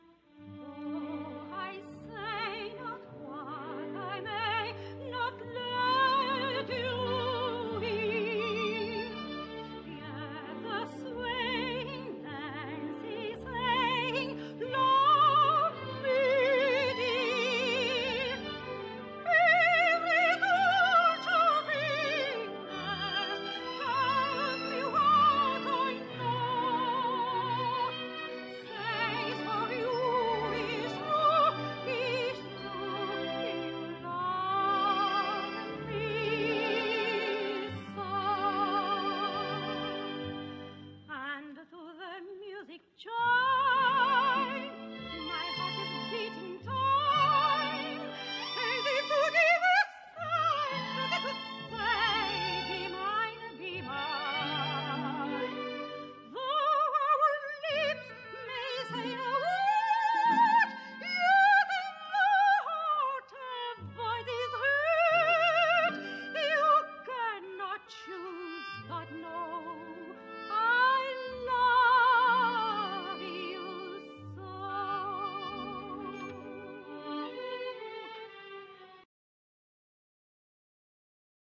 Lyric Soprano